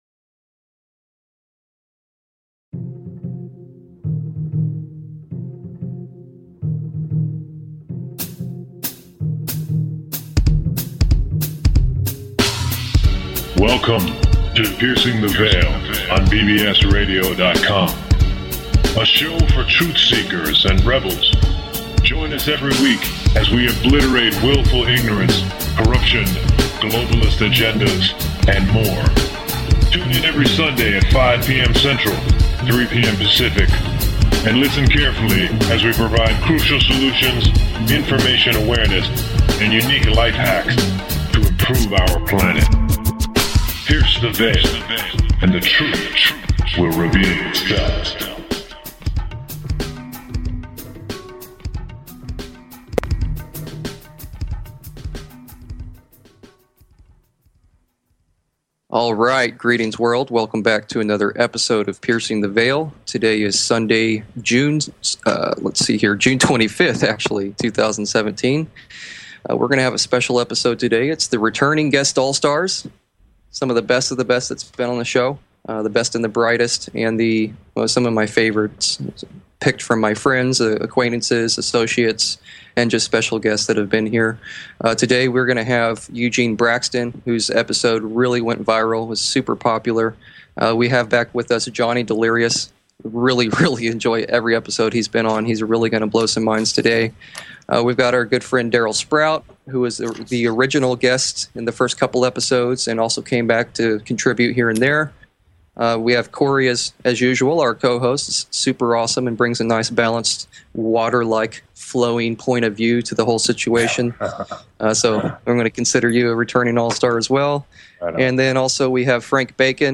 This is a special Collaboration Episode featuring the best of the best from past shows. Excellent stories shared, ideas discussed, and all around Mindblowing!